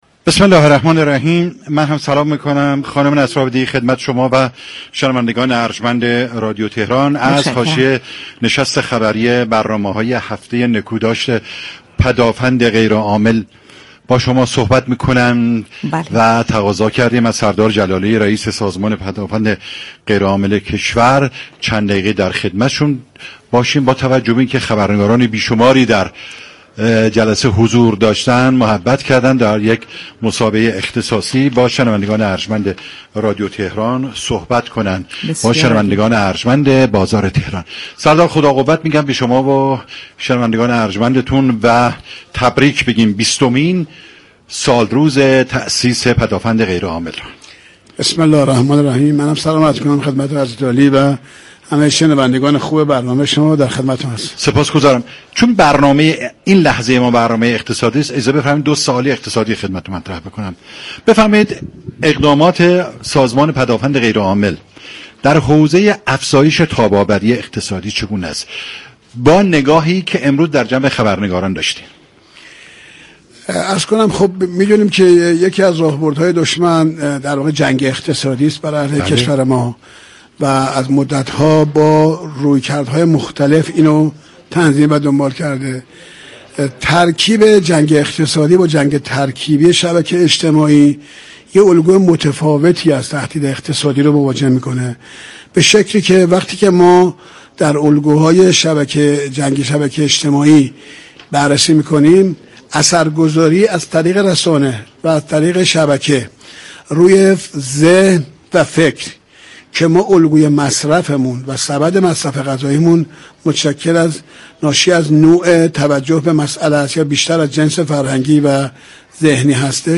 به گزارش پایگاه اطلاع رسانی رادیو تهران، سردار غلامرضا جلالی رئیس سازمان پدافند غیرعامل كشور در گفت و گو با «بازار تهران» درباره اقدامات این سازمان در حوزه افزایش تاب‌آوری اقتصادی اینگونه اظهار داشت: یكی از راهبردهای دشمن جنگ اقتصادی علیه كشورمان است و از مدت‌ها پیش با رویكرد‌های مختلف این هدف را دنبال كرده است.